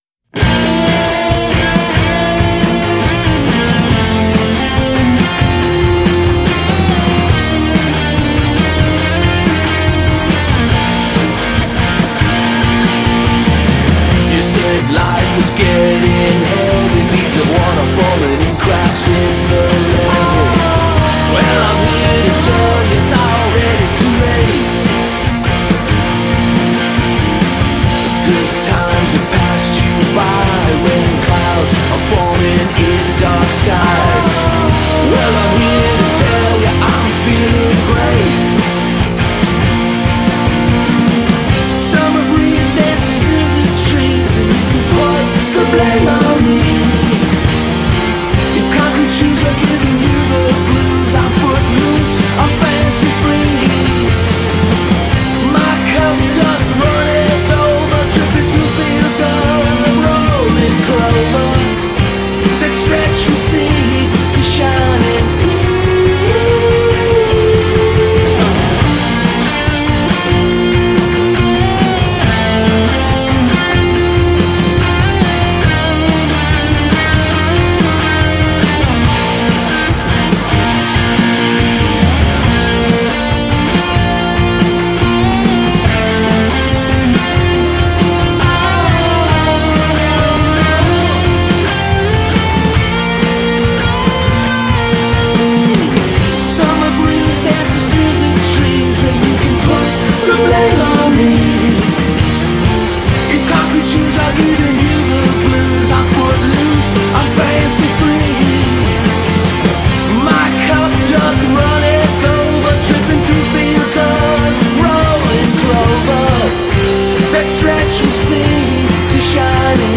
recorded at Fidelitorium in North Carolina